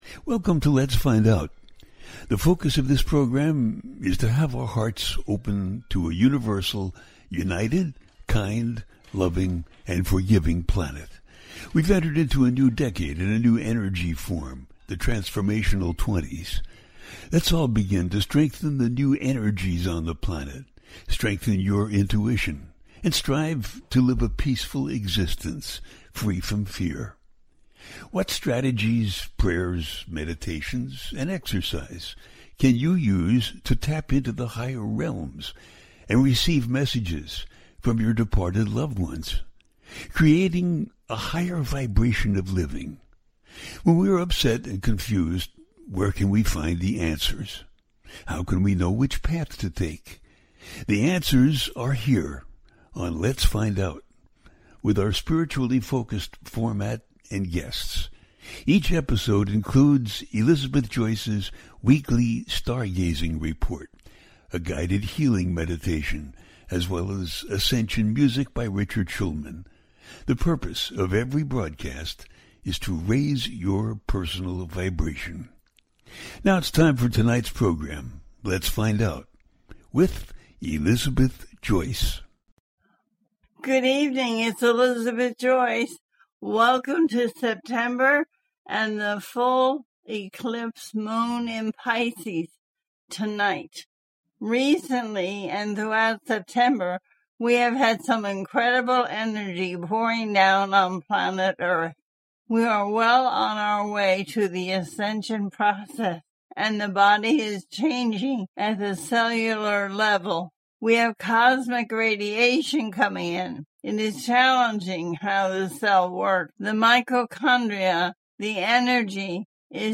The Shape Chaning Energies Of September 2025 Update - A teaching show